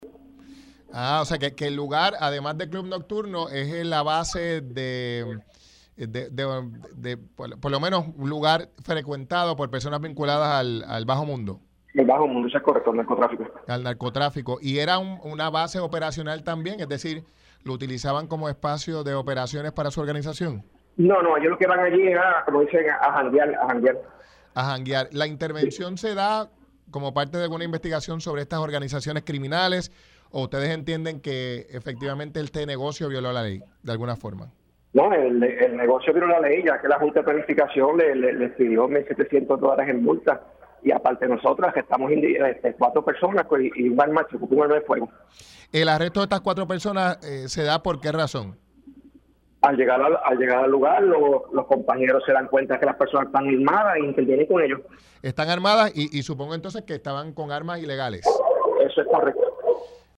Hemos recibido información de que en el lugar llegan individuos armados pertenecientes a organizaciones criminales y se hizo una vigilancia e intervenimos“, destacó el inspector en entrevista para Pega’os en la Mañana al aclarar que desde el lugar no se operaba como tal una organización.